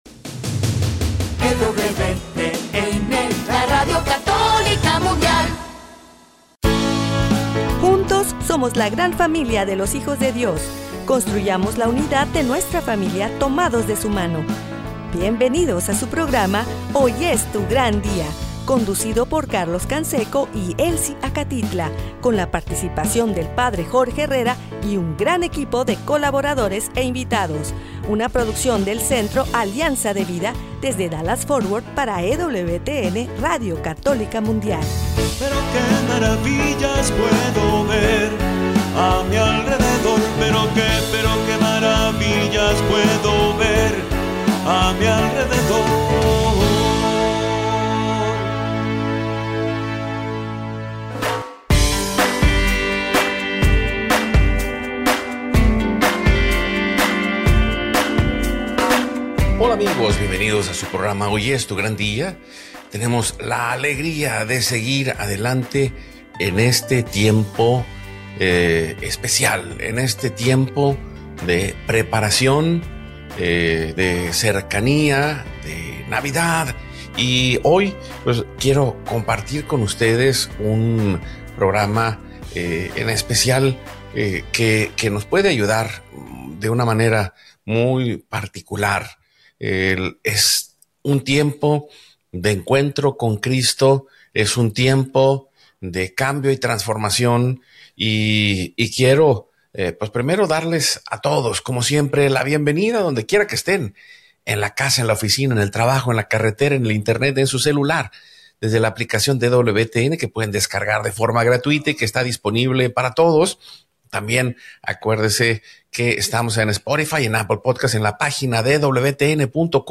Hoy es tu gran día es un programa de evangelización en vivo desde Dallas, Texas, para vivir en plenitud la vida en Cristo, caminando junto a El para ver las maravillas a nuestro alrededor en la vida diaria como discípulos.